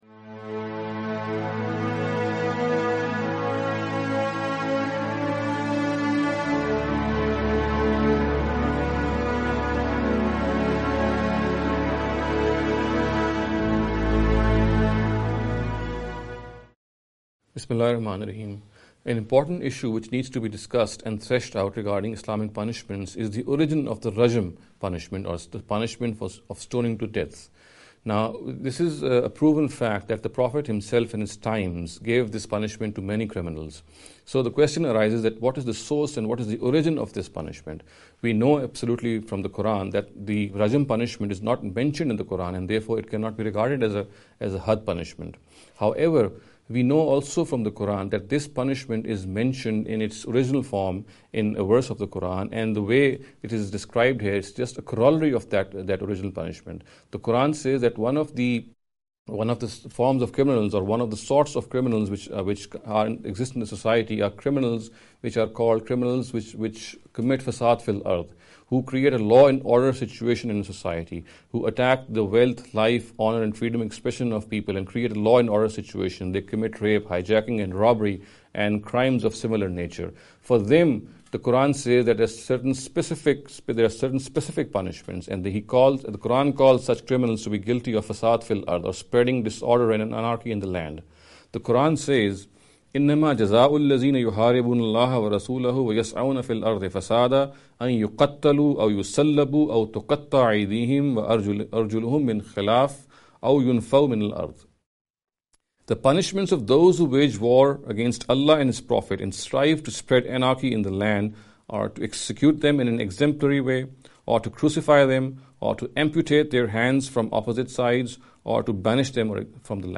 This lecture series will deal with some misconception regarding the Islamic Punishments.